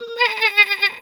sheep_2_baa_03.wav